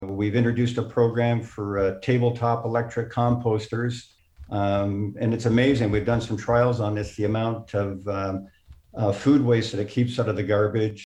Mayor Jenkins made this point during a meeting of the Hastings County Planning committee this week.